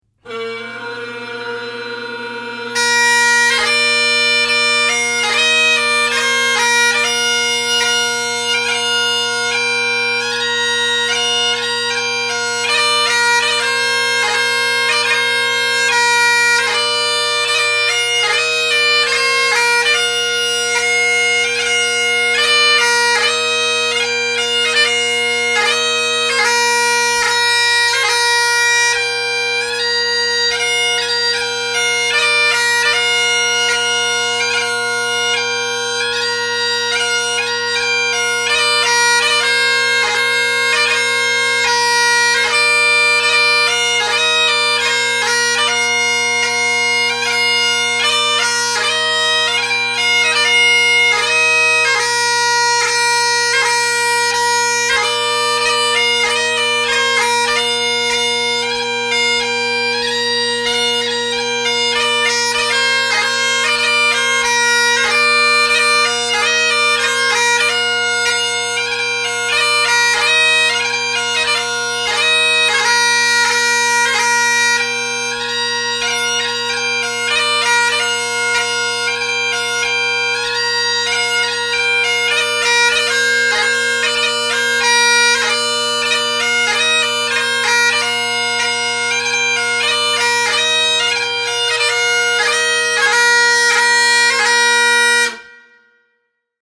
Halifax Bagpiper
Lord Lovat’s Lament (Slow or quick tempo.